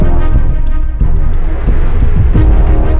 epic.wav